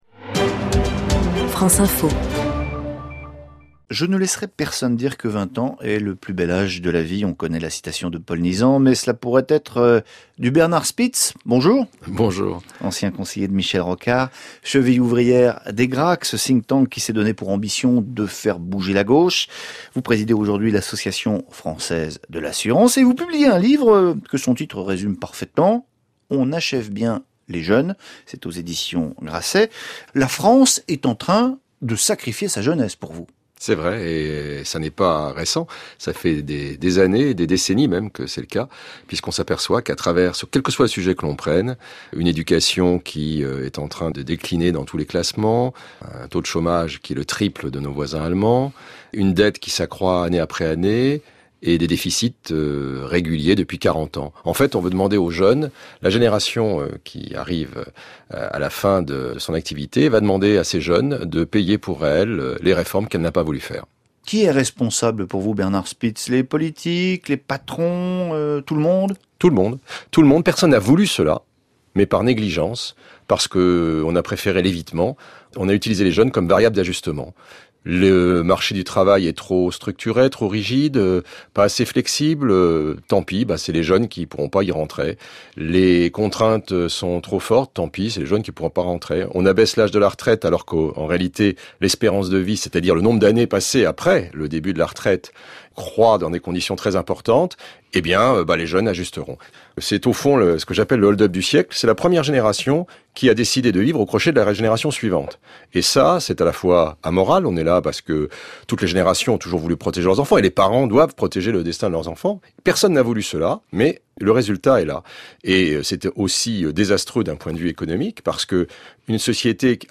ce matin sur France Info un interview